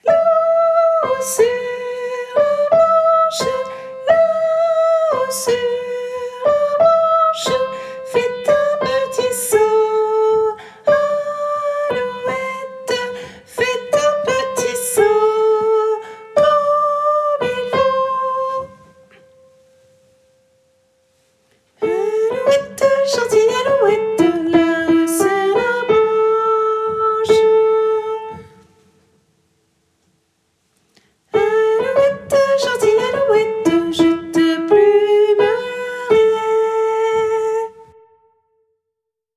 - Œuvre pour choeur à 4 voix mixtes (SATB)
- chanson populaire de Lorraine
MP3 versions chantées
Tenor